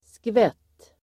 Uttal: [skvet:]